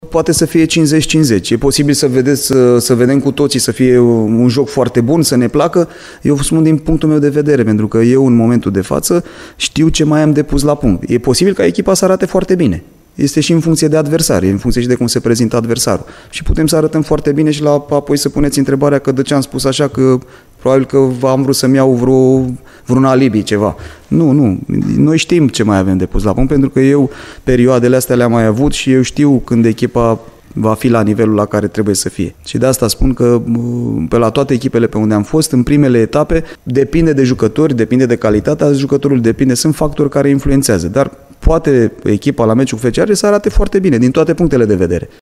Despre starea actuală a formațiilor și despre posibilitatea de a vedea un joc bun, a vorbit, înainte de plecarea spre Trivale, antrenorul arădenilor Ilie Poenaru: